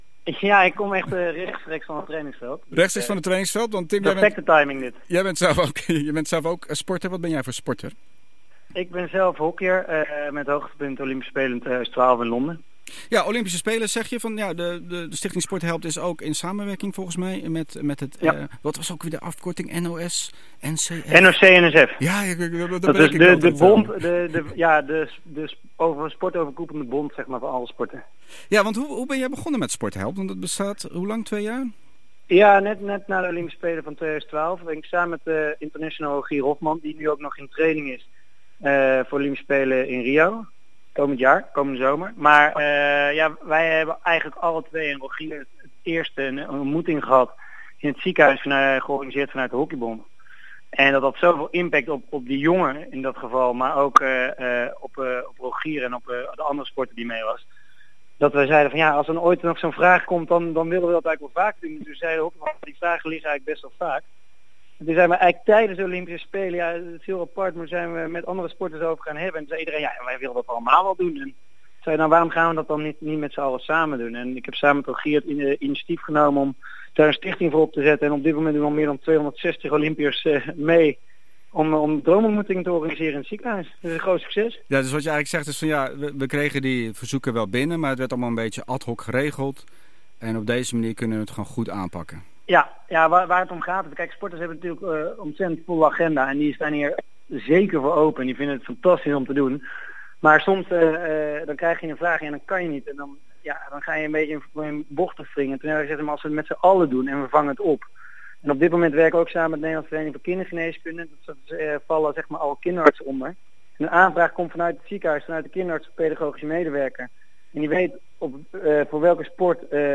Tim deelt in AmsterdamLight zijn verhaal en komt letterlijk van het sportveld afgerend. De ontmoetingen laten een diepe indruk achter op de topsporter.